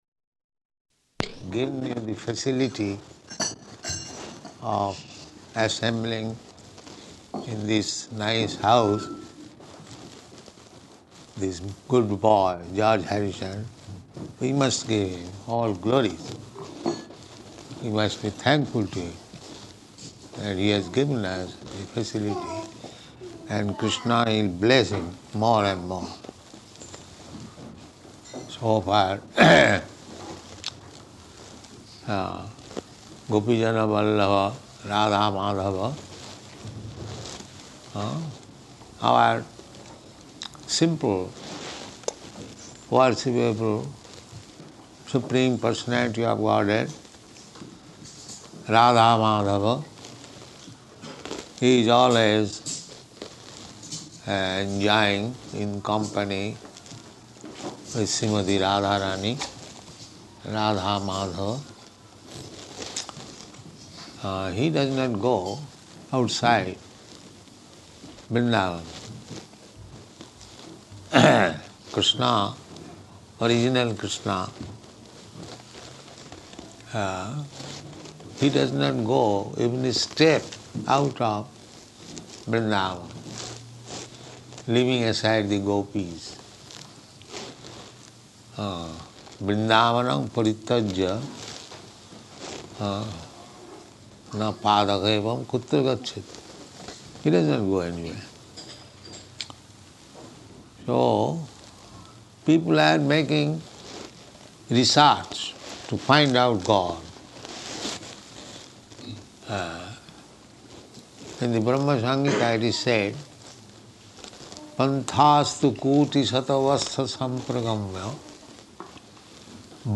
Arrival Address at Bhaktivedanta Manor
Type: Lectures and Addresses
Location: London